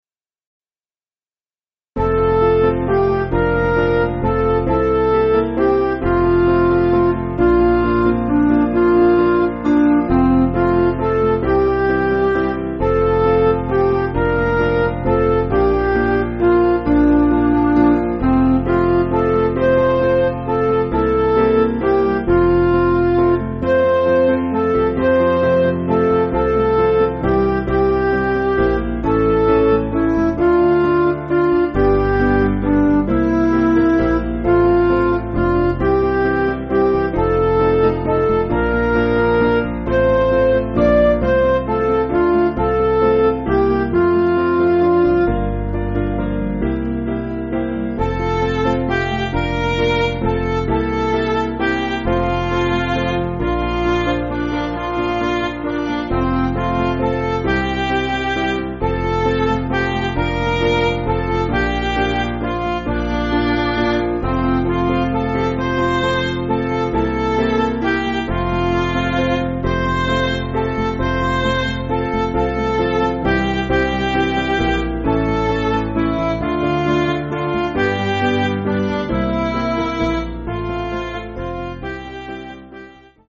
Vocals and Band   264.3kb Sung Lyrics 2.6mb